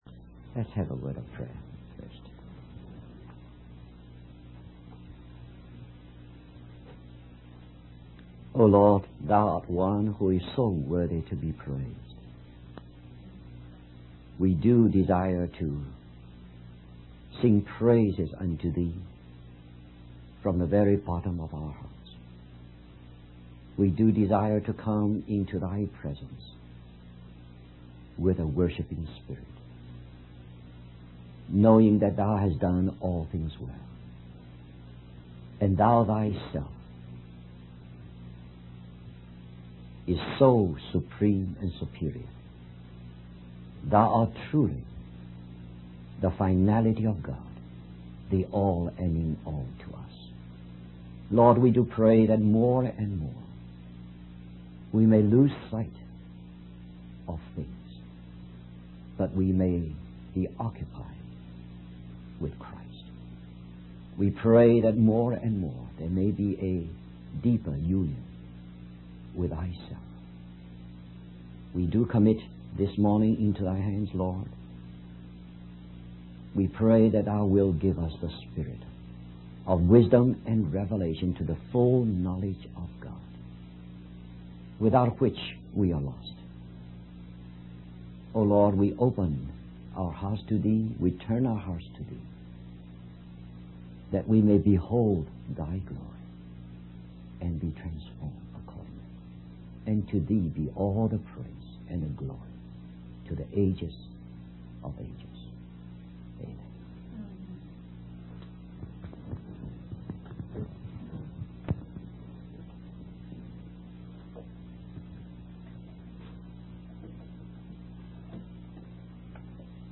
In this sermon, the preacher focuses on the repetition of the word 'better' in the book of Hebrews.